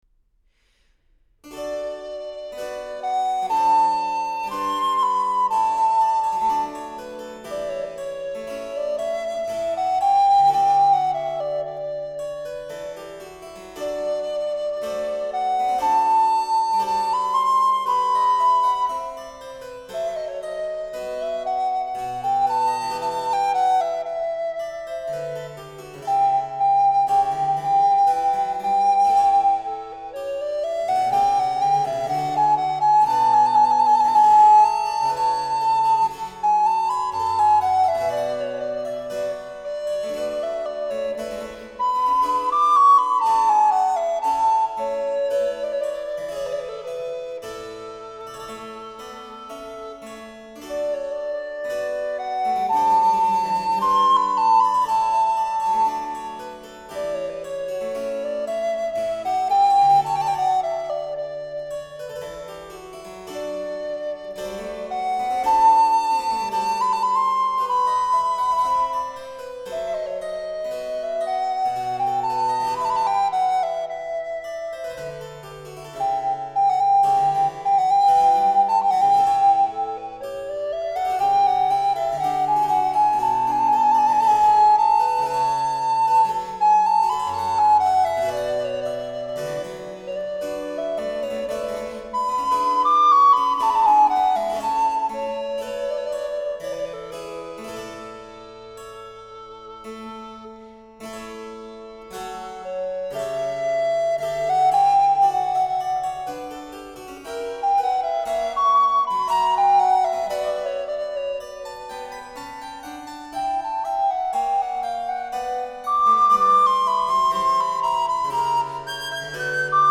A02-02 Sonata in B minor_ Largo e doce | Miles Christi
A06-02-Sonata-in-B-minor_-Largo-e-doce.mp3